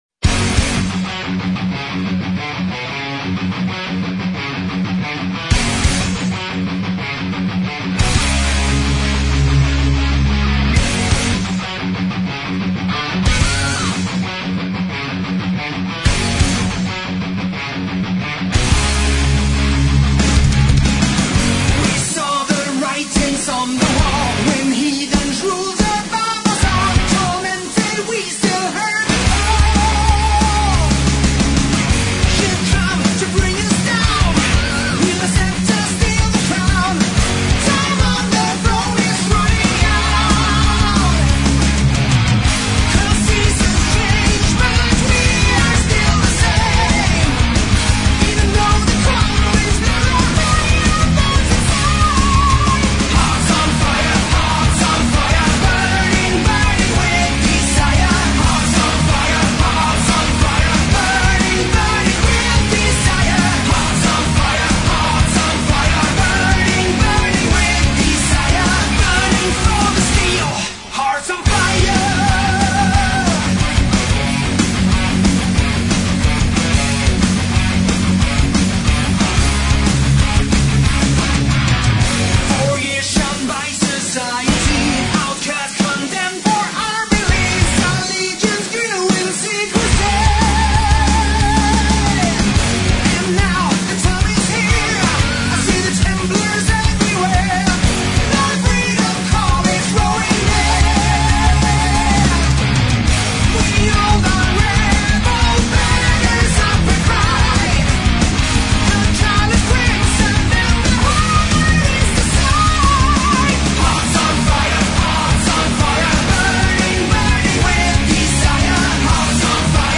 Rock Metal